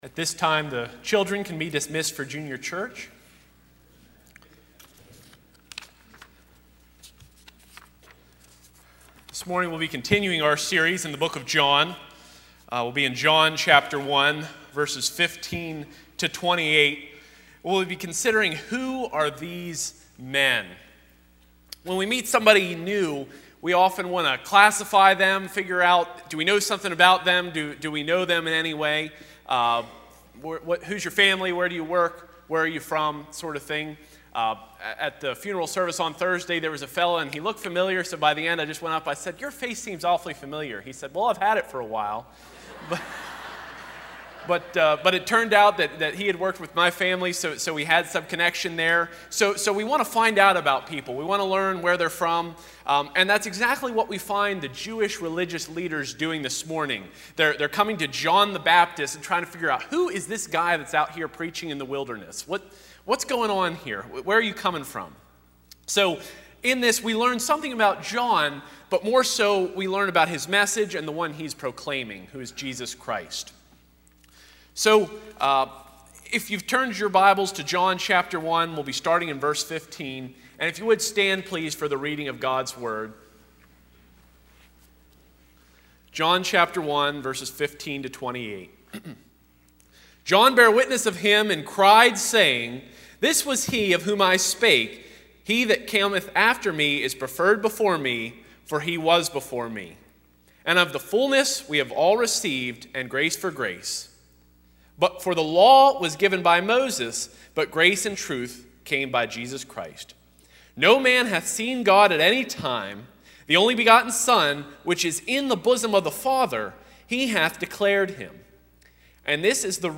John 1:15-28 Service Type: Sunday 9:30AM Who Are These Men?